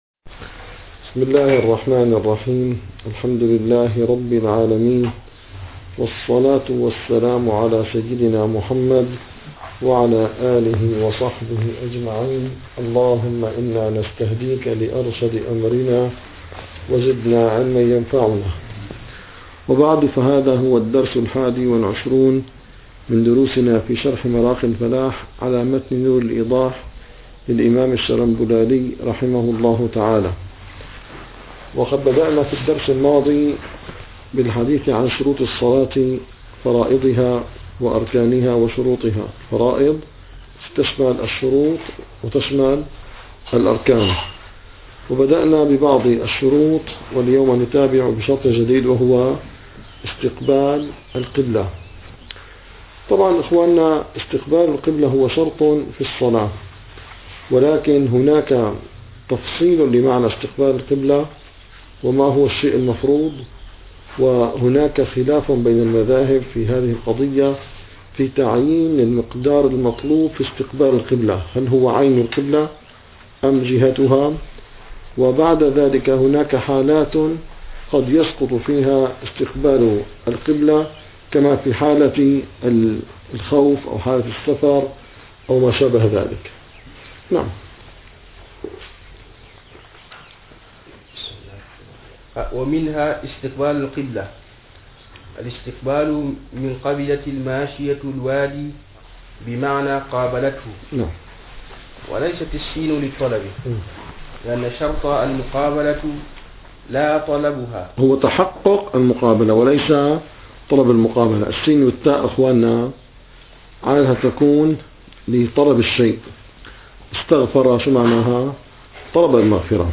- الدروس العلمية - الفقه الحنفي - مراقي الفلاح - 21- استقبال القبلة